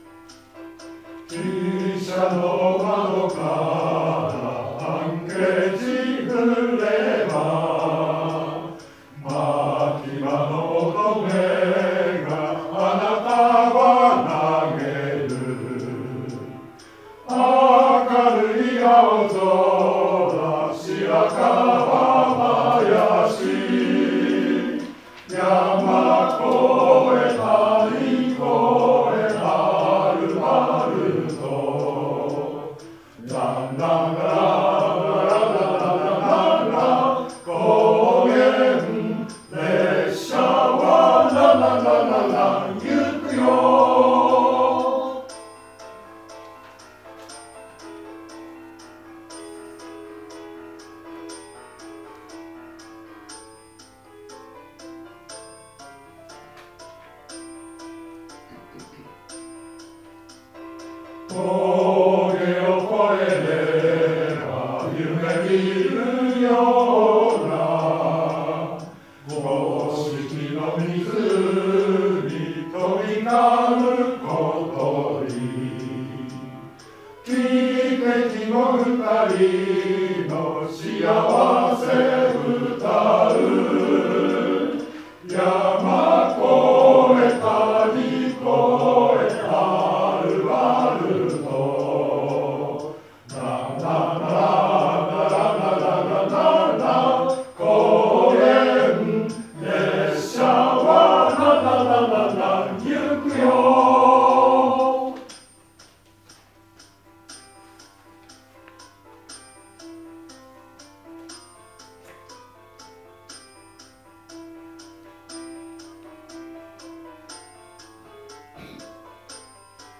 「高原列車は行く」は、通して歌いました。
今日はスピーカーがありませんでしたが、メトロノームを音源に入れるなどして
（音源に２番、３番が入っているため、　２番と３番は同じ歌詞で歌う）